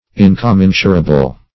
Incommensurable \In`com*men"su*ra*ble\, n.